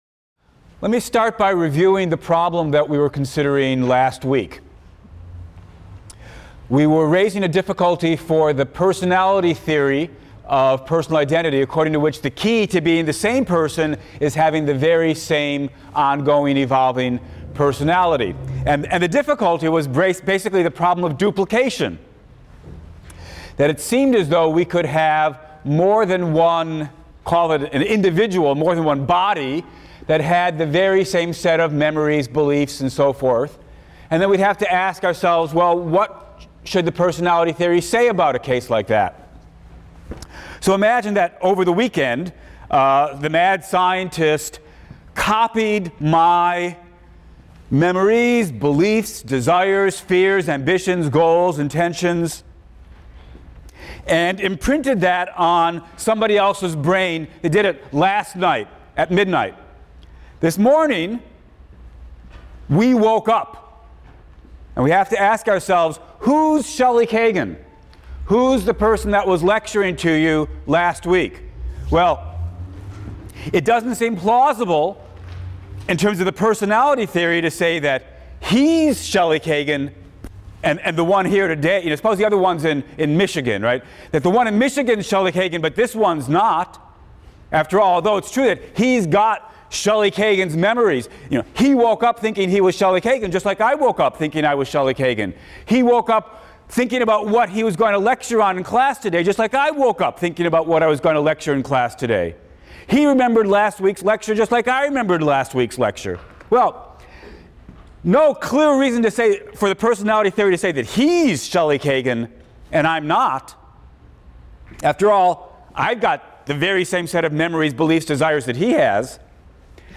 PHIL 176 - Lecture 13 - Personal Identity, Part IV: What Matters?